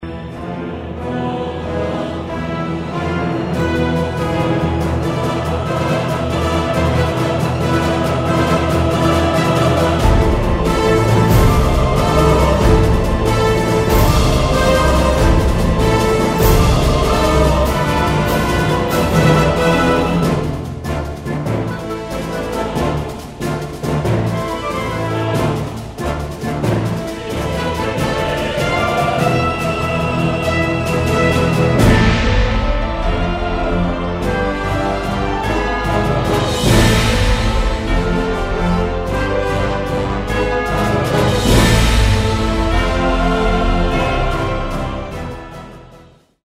This is a professional backing track of the song
Instrumental
orchestral